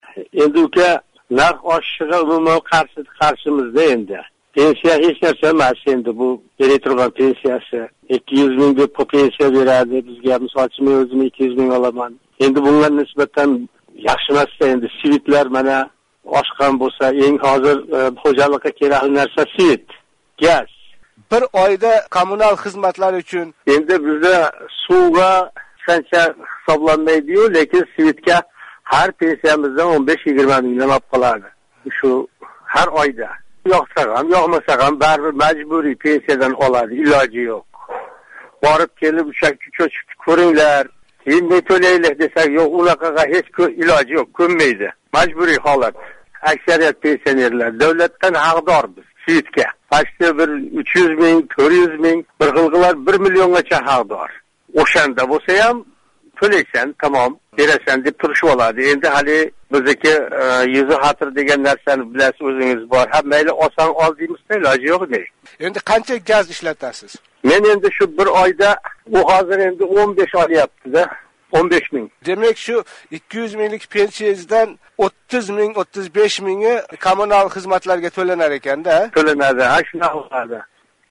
Пенсионер
суҳбат